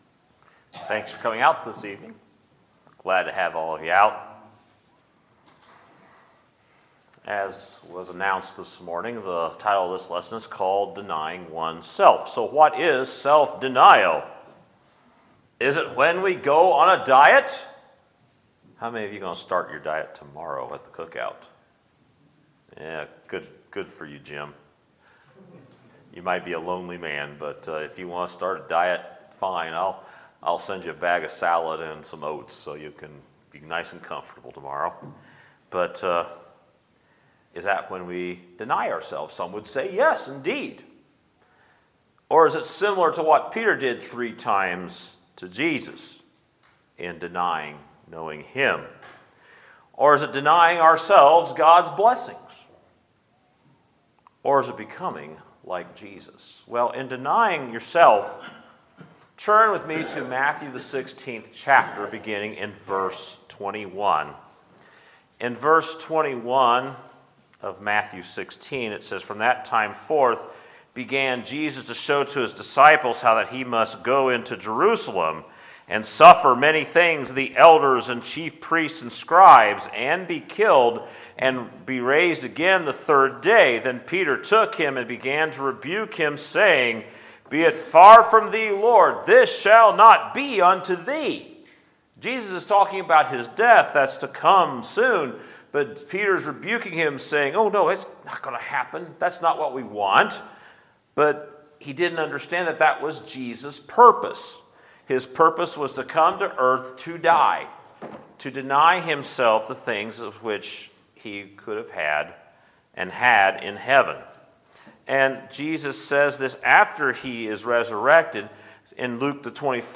Sermons, May 27, 2018